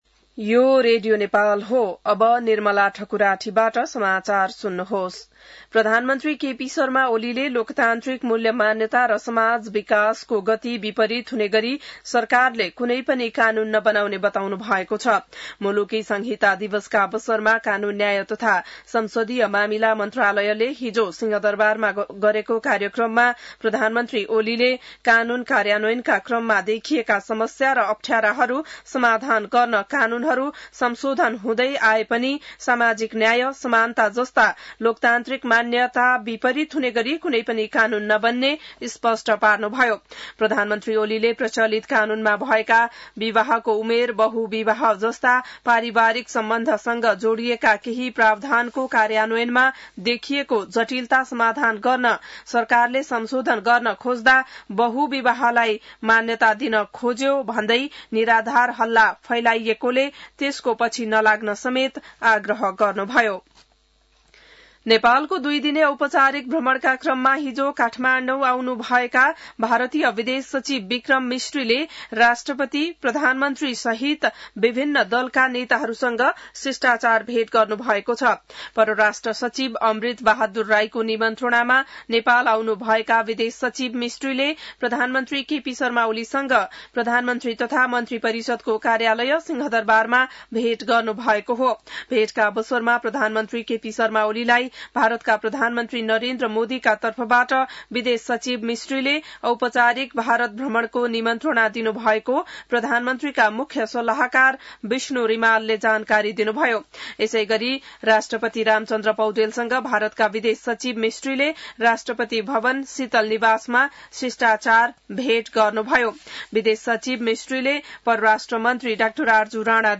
बिहान १० बजेको नेपाली समाचार : २ भदौ , २०८२